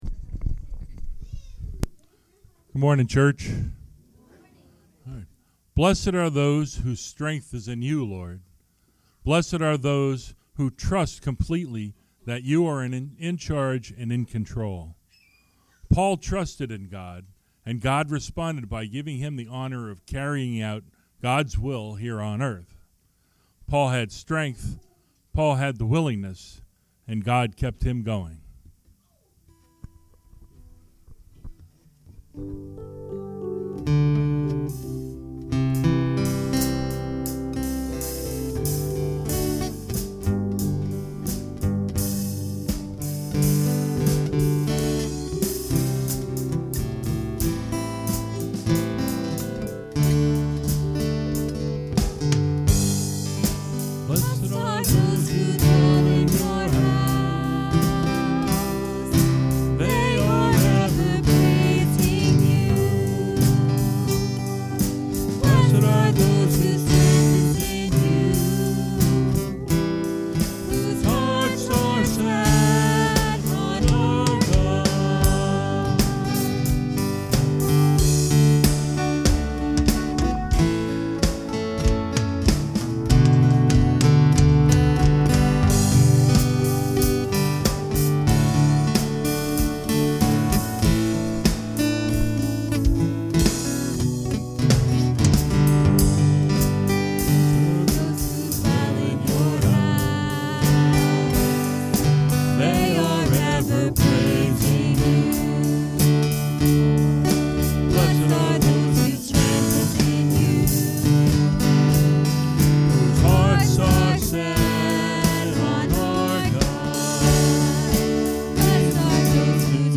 Acts 27:1-27 Service Type: Sunday Morning Worship Intro